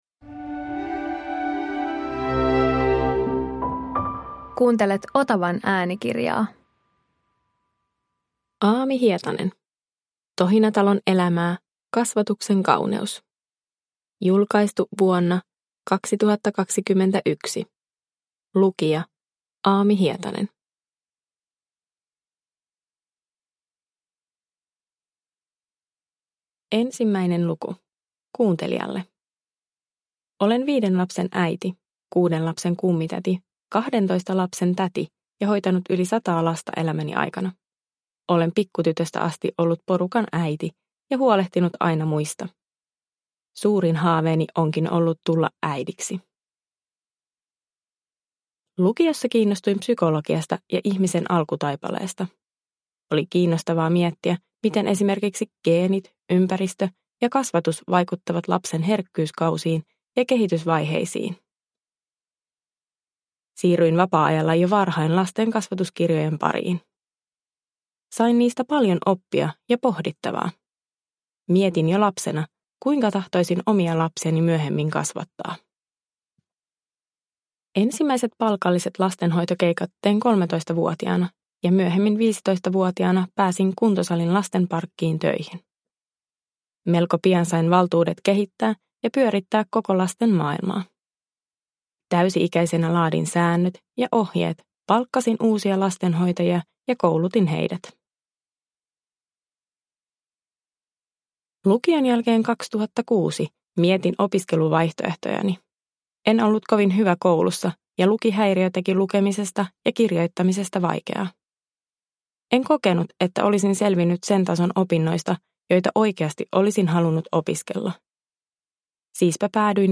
Tohinatalon elämää – Ljudbok – Laddas ner